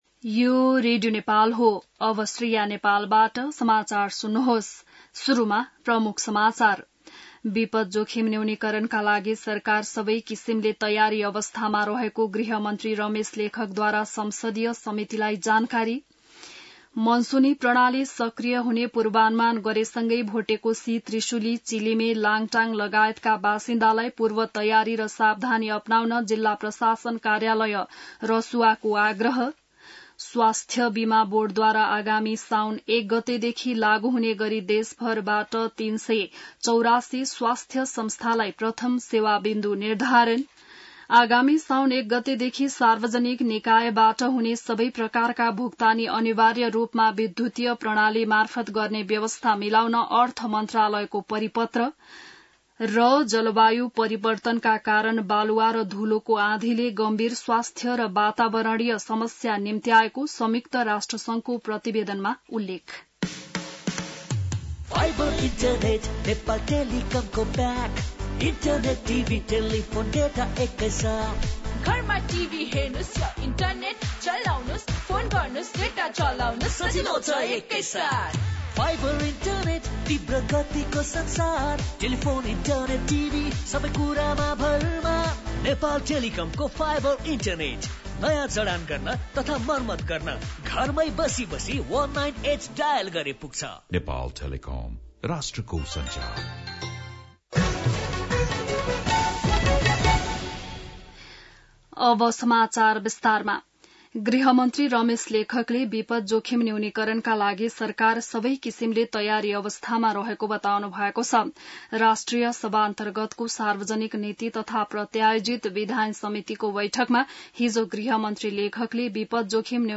बिहान ७ बजेको नेपाली समाचार : ३० असार , २०८२